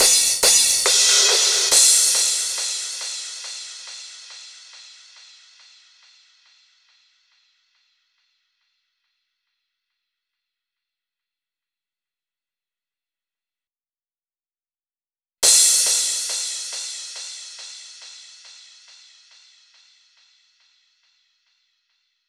VTDS2 Song Kit 12 Rap U Got Me Rocking Crash Mix.wav